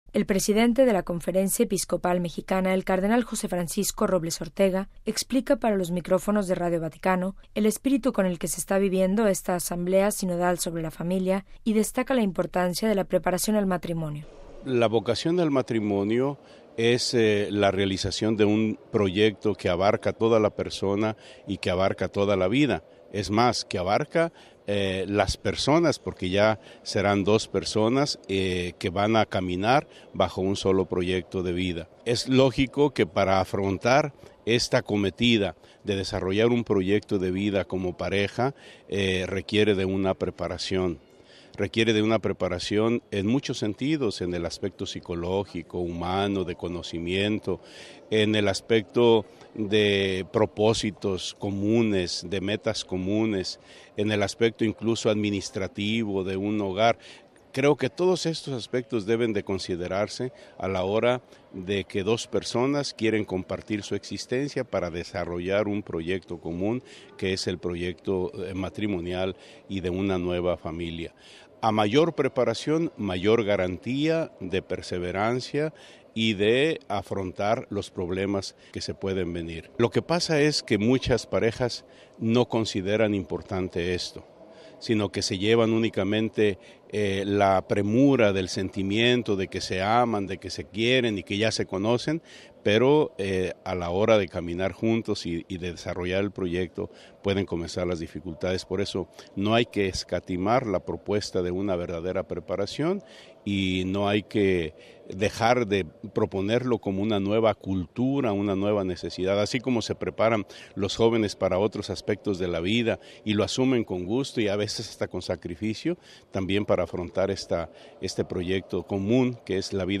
MP3 El presidente de la Conferencia Episcopal Mexicana (CEM), el Cardenal José Francisco Robles Ortega explica para los micrófonos de Radio Vaticano el espíritu con el que se está viviendo esta Asamblea sinodal sobre la familia y destaca la importancia de la preparación al matrimonio.